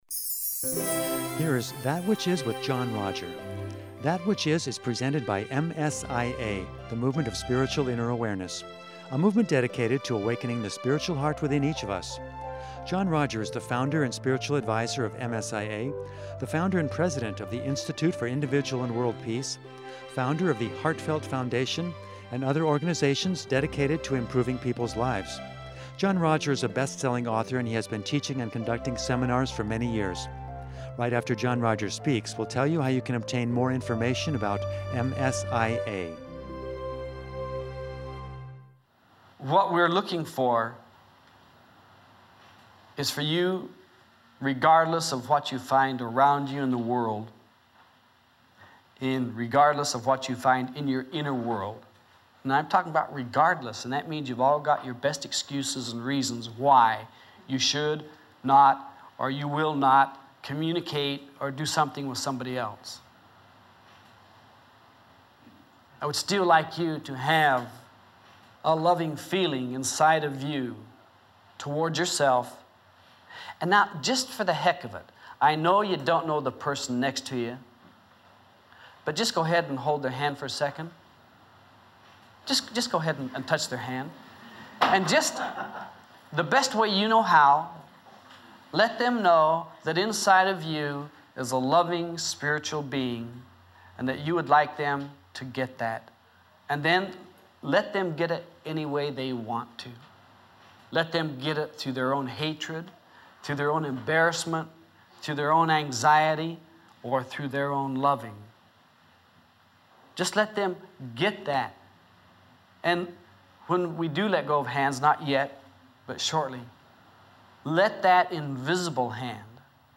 In this sweet seminar